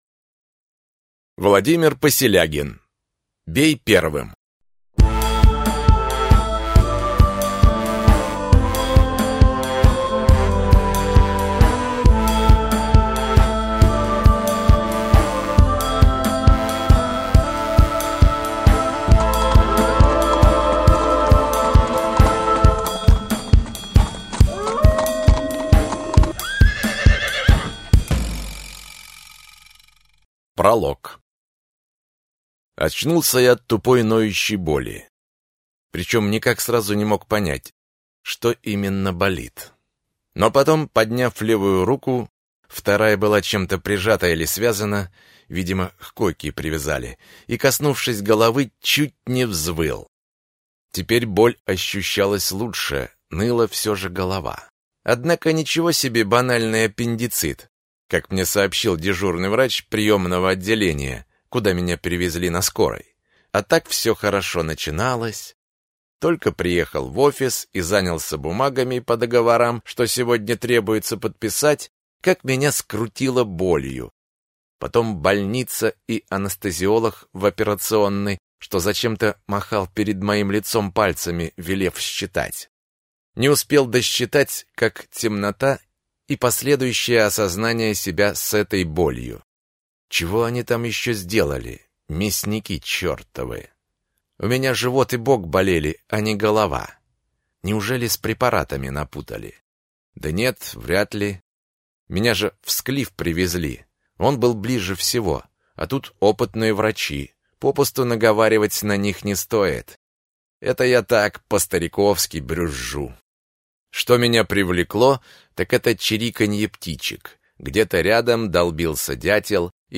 Аудиокнига Русич. Бей первым | Библиотека аудиокниг
Прослушать и бесплатно скачать фрагмент аудиокниги